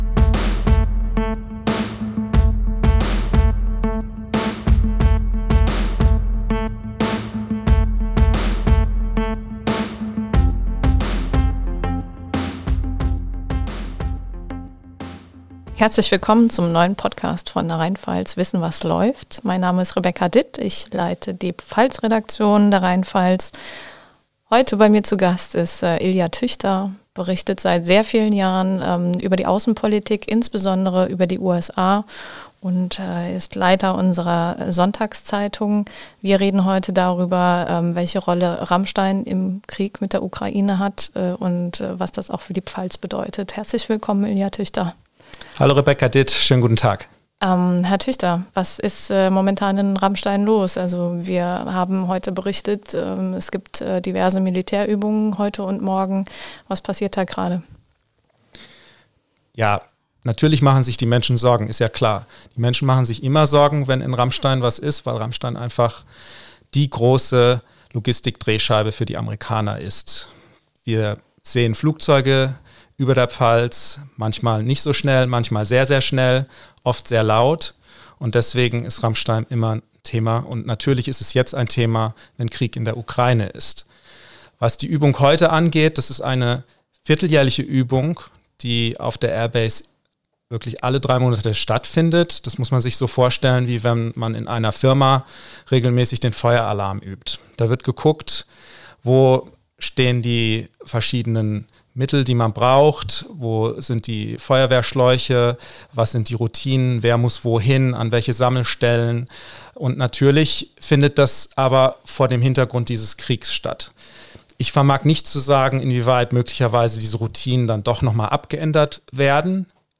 Der RHEINPFALZ-Podcast "Wissen was läuft" liefert spannende Hintergründe und Analysen zu den Top Stories, die die Pfalz bewegen. Hier berichten RHEINPFALZ-Redakteurinnen und -redakteure über ihre Recherchen und erklären verständlich die Zusammenhänge.